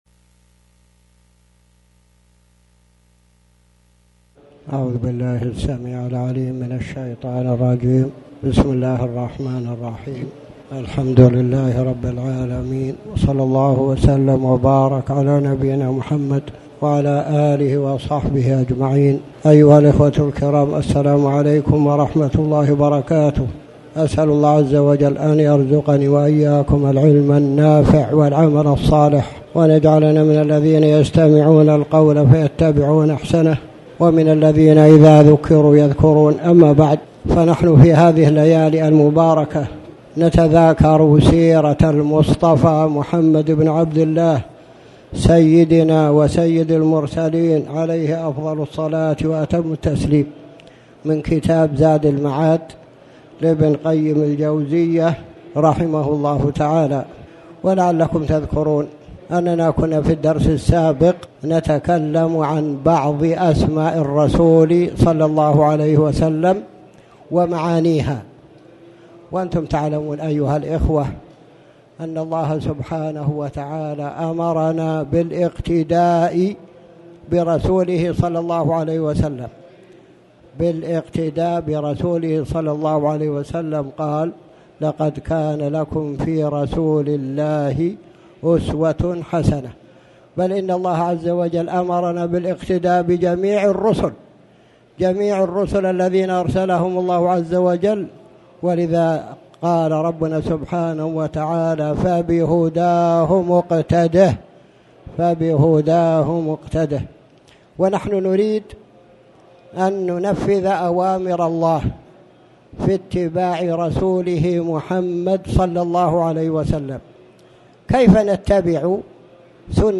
تاريخ النشر ٧ رجب ١٤٣٩ هـ المكان: المسجد الحرام الشيخ